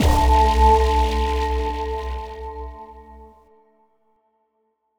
Synth Impact 09.wav